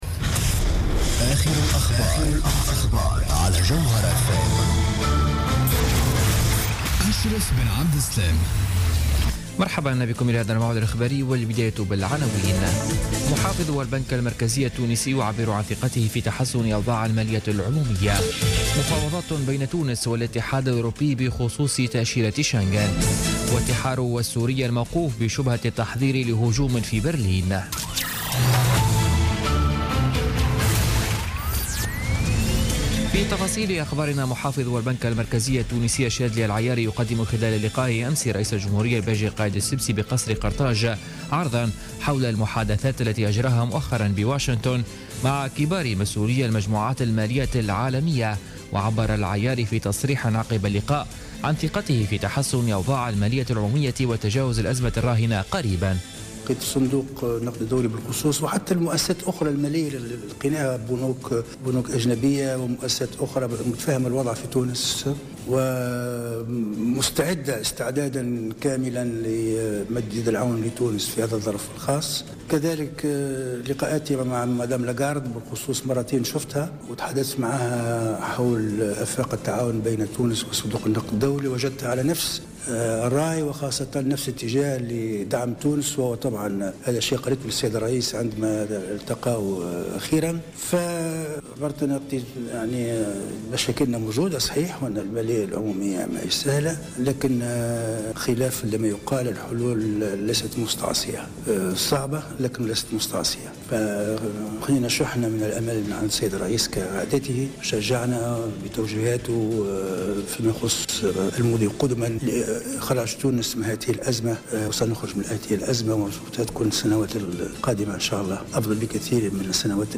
نشرة أخبار منتصف الليل ليوم الخميس 13 أكتوبر 2016